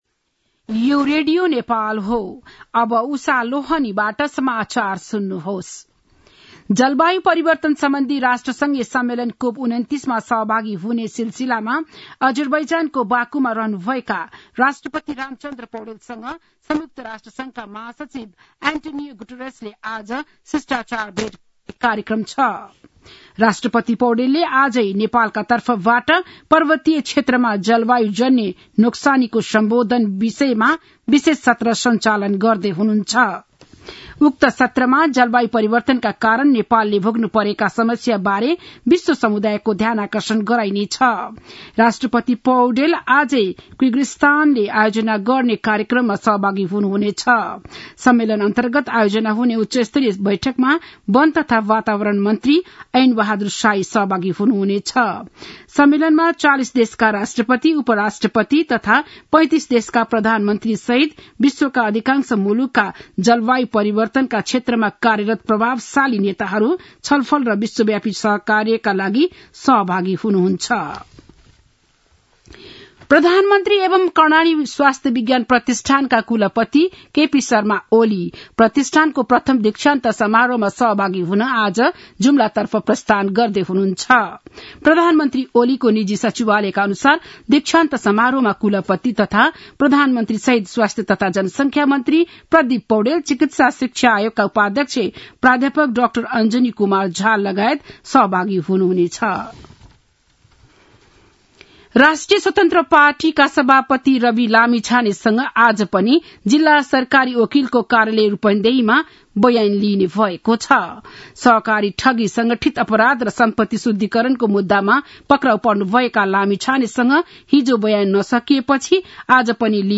बिहान ११ बजेको नेपाली समाचार : २९ कार्तिक , २०८१
11-am-nepali-news-1-1.mp3